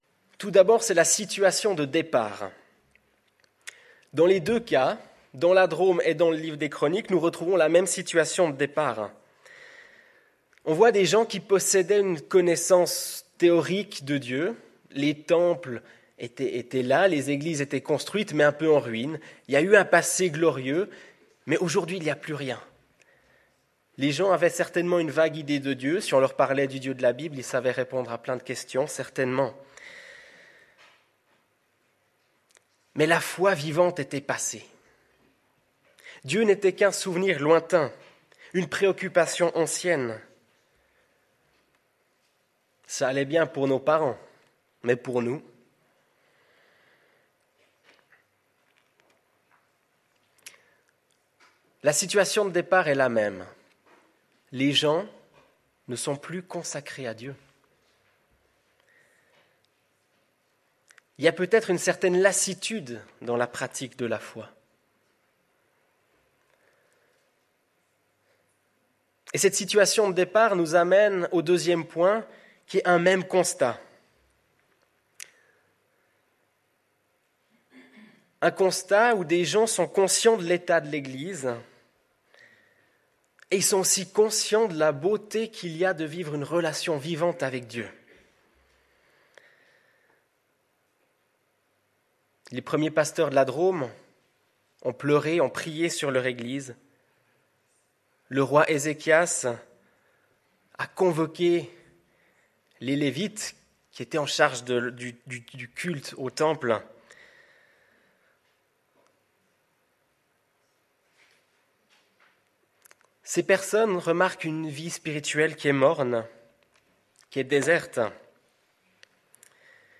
Note: Le début du message est manquant.